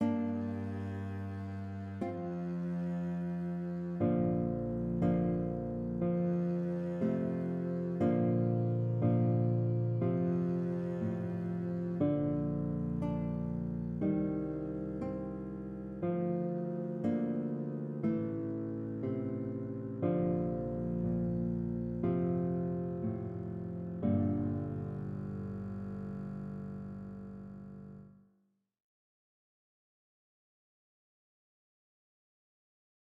Pour des raisons de clarté auditive, les exemples audios seront ici donnés avec des sons de violoncelle, ceux ci étant préférables aux sons de voix synthétiques.
Voici maintenant l’accompagnement joué par un luth et une contrebasse (virtuels..):